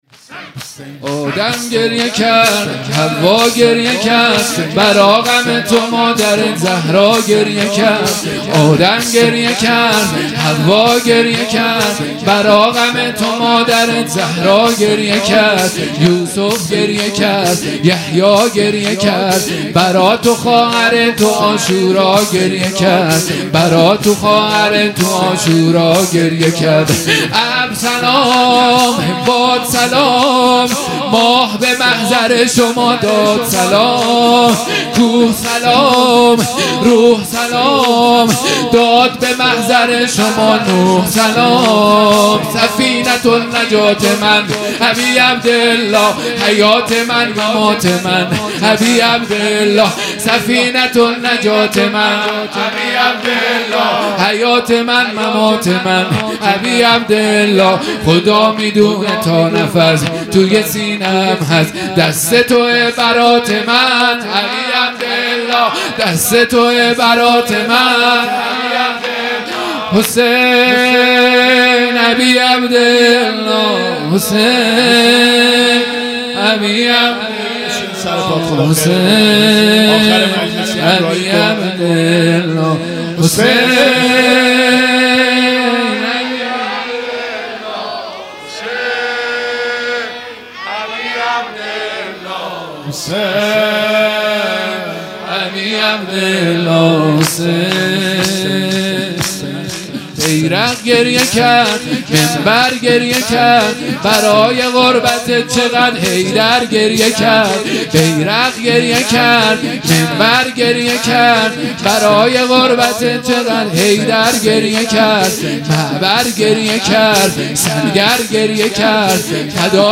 مراسم عزاداری محرم الحرام ۱۴۴۳_شب ششم
0 0 شور ۱